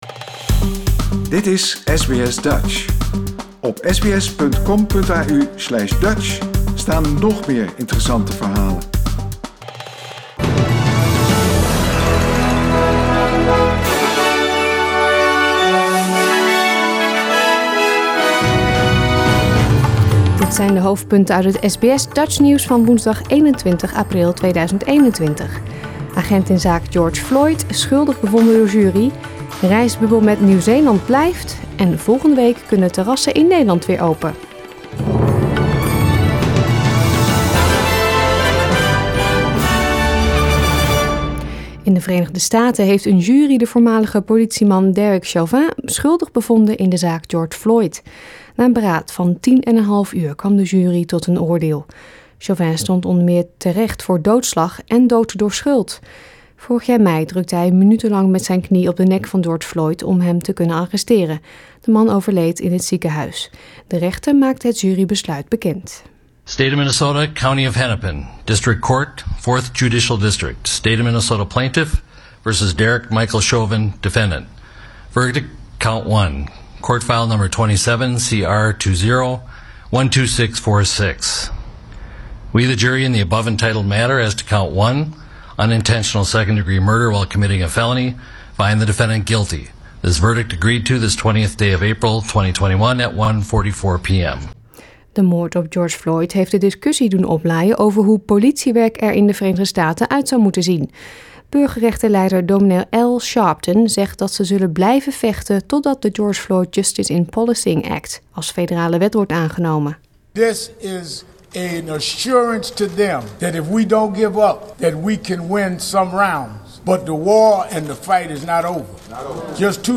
Nederlands/Australisch SBS Dutch nieuwsbulletin woensdag 21 april 2021